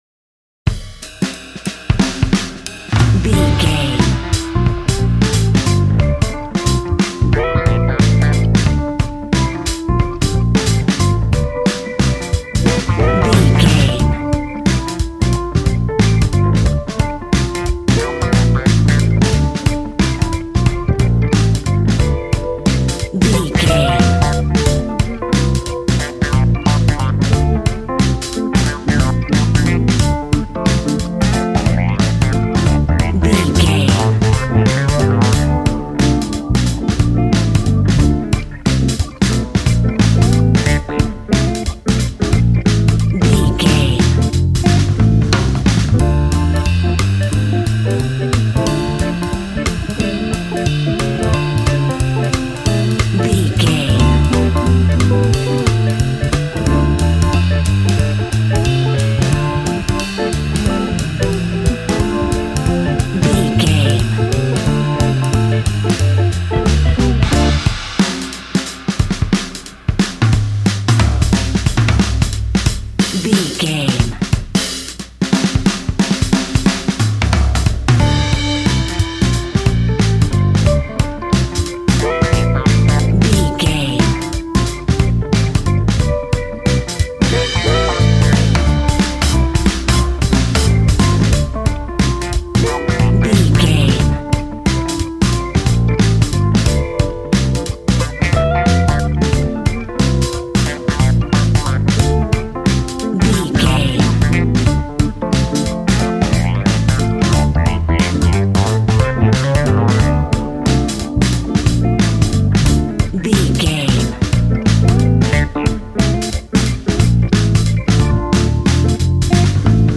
Aeolian/Minor
relaxed
smooth
synthesiser
drums
80s